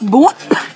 Play, download and share Bwop! original sound button!!!!
bwop.mp3